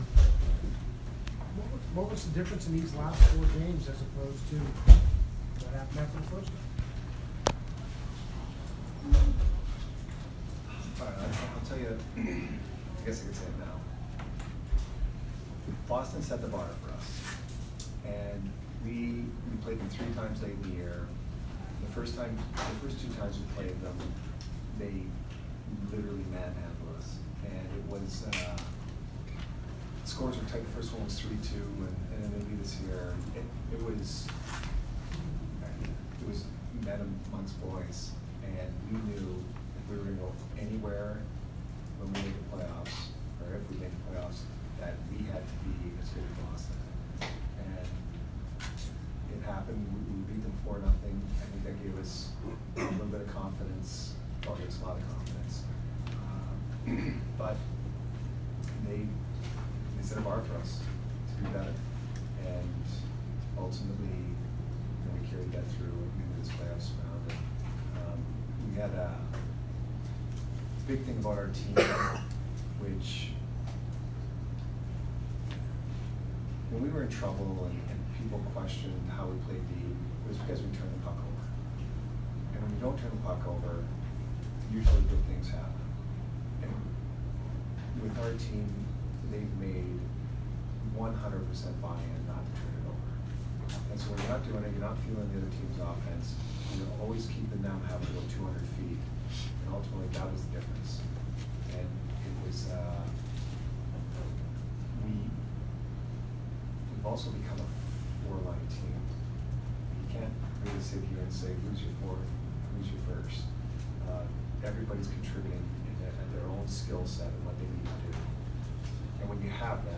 Jon Cooper post-game 5/6